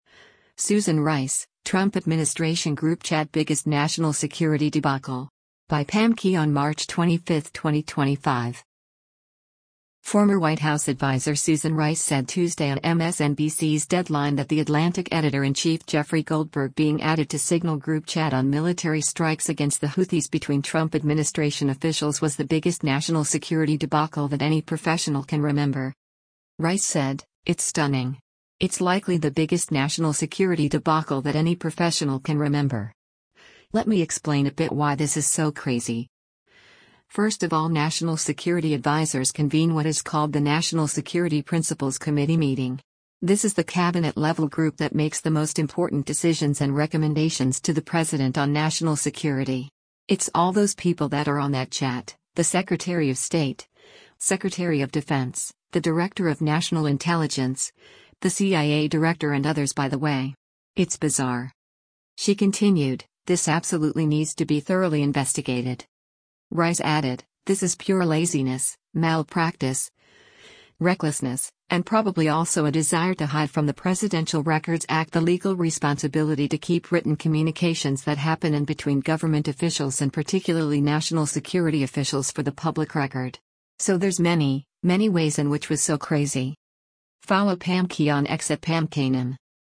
Former White House adviser Susan Rice said Tuesday on MSNBC’s “Deadline” that The Atlantic editor-in-chief Jeffrey Goldberg being added to Signal group chat on military strikes against the Houthis between Trump administration officials was “the biggest national security debacle that any professional can remember.”